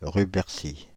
Rubercy (French pronunciation: [ʁybɛʁsi]
Fr-Paris--Rubercy.ogg.mp3